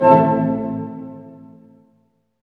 Index of /90_sSampleCDs/Roland L-CD702/VOL-1/HIT_Dynamic Orch/HIT_Tutti Hits